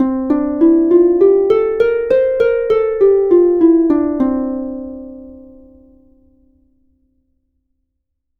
Escala mixolidia
arpa
sintetizador